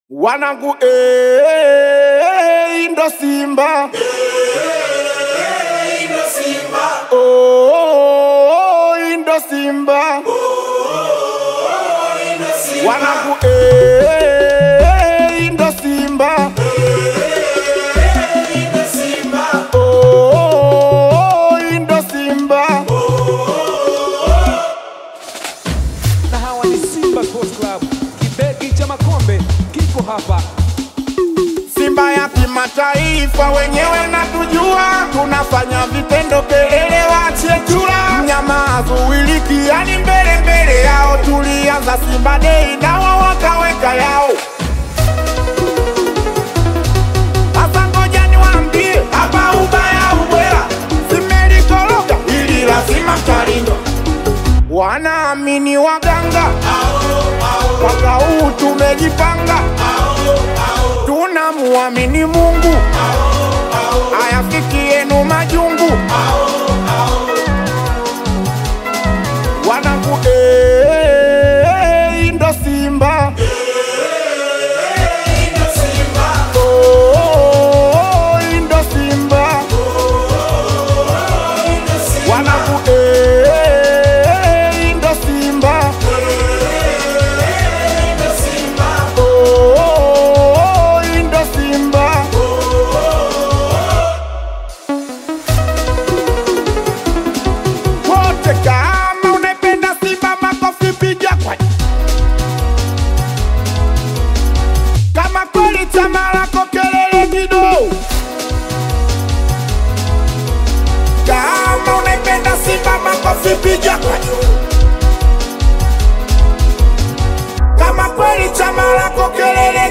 a high-energy singeli track
fast-paced singeli beats